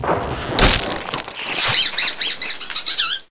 ratappear.ogg